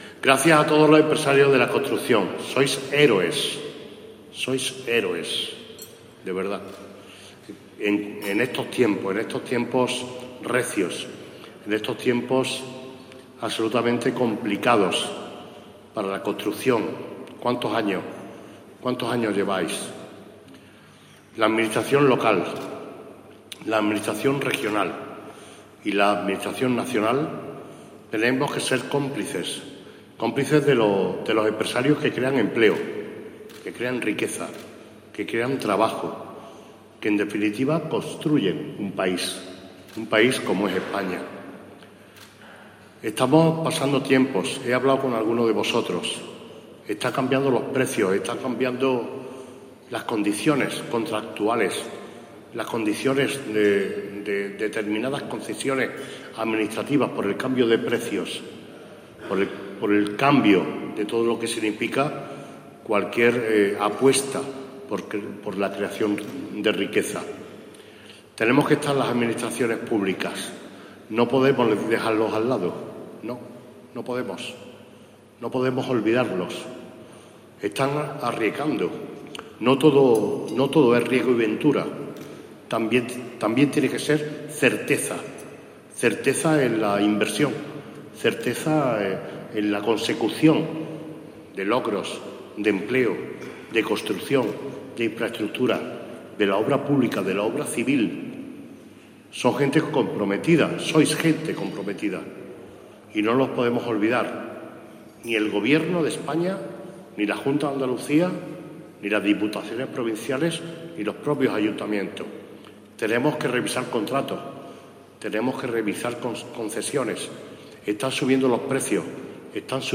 El acto tuvo lugar en el Hotel Convento La Magdalena, escenario en el que se reunieron una amplia representación de empresarios del sector acompañados para la ocasión por autoridades como el delegado del Gobierno de España en Andalucía, Pedro Fernández, la consejera de Fomento de la Junta de Andalucía, Marifrán Carazo, la viceconsejera de Agricultura, Ana Corredera, o el concejal y parlamentario andaluz José Ramón Carmona.
Durante su intervención, el Alcalde agradeció la labor en beneficio de la sociedad que realizan los empresarios de la construcción a pesar de los tiempos recios y complicados para el sector, defendiendo la idea de que "la administración local, regional y nacional tenemos que ser cómplices de los empresarios que crean empleo, riqueza y trabajo, que en definitiva construyen un país como es España".
Cortes de voz